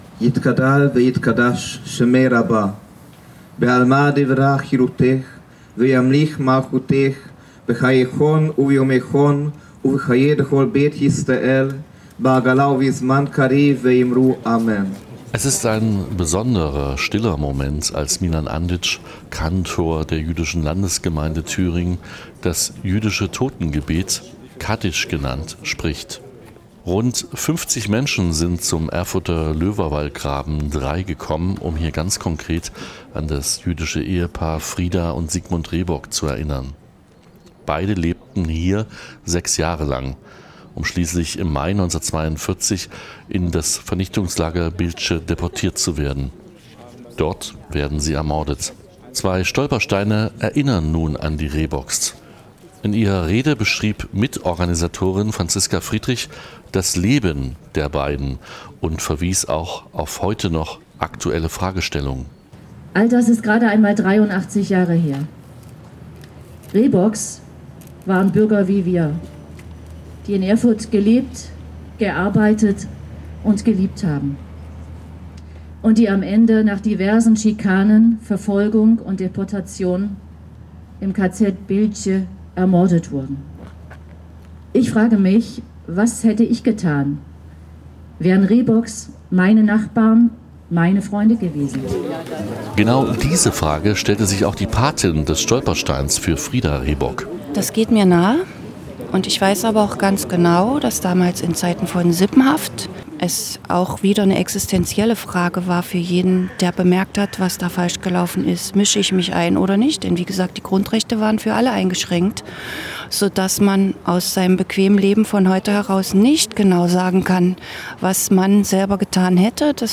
Wir von Radio F.R.E.I waren vor Ort am Löberwallgraben - hier paar Eindrücke und Stimmen.